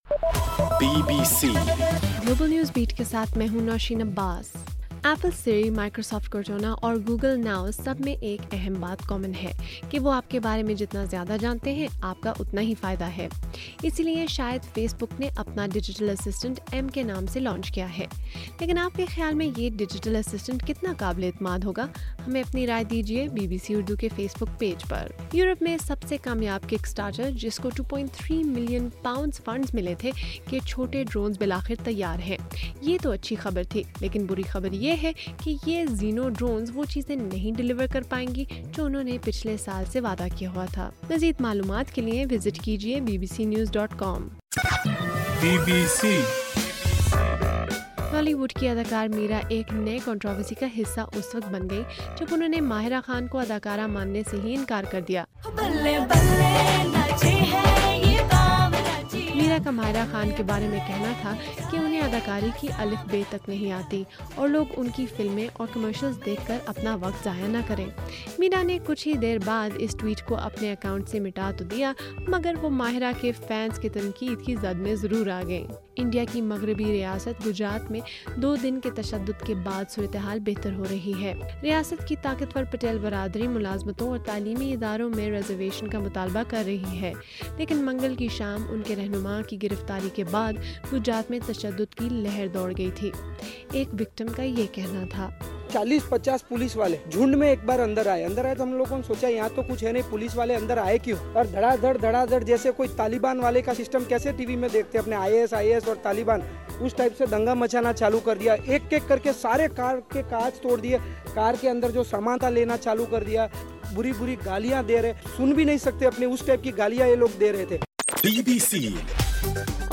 اگست 28: صبح 1 بجے کا گلوبل نیوز بیٹ بُلیٹن